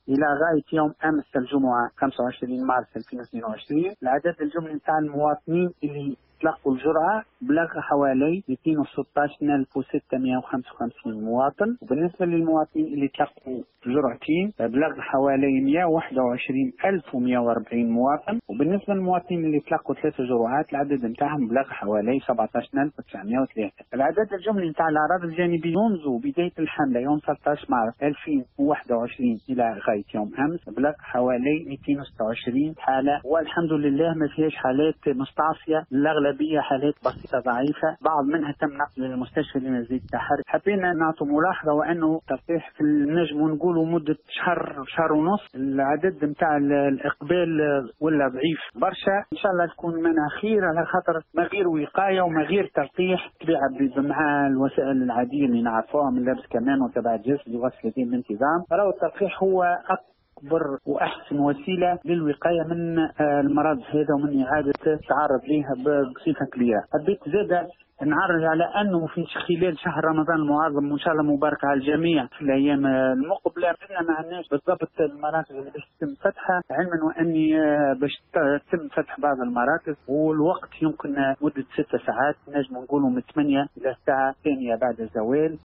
سيدي بوزيد : من المنتظر فتح عدد من مراكز التلقيح خلال شهر رمضان ( تصريح )